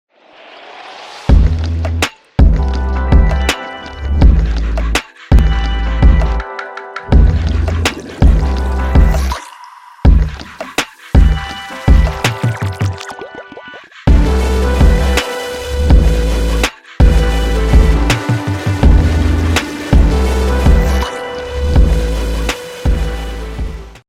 مجموعه پرکاشن